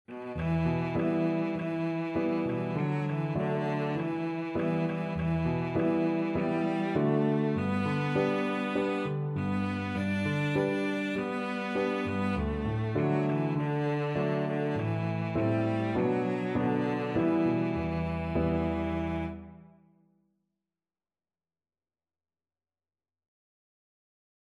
Cello
Traditional Music of unknown author.
E minor (Sounding Pitch) (View more E minor Music for Cello )
2/4 (View more 2/4 Music)
Moderato